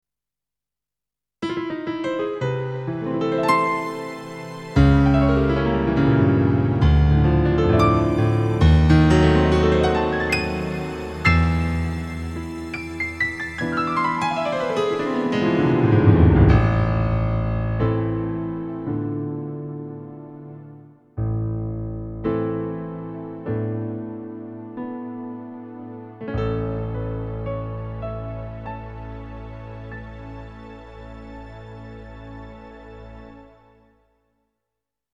lg-Demo Piano TR 2.mp3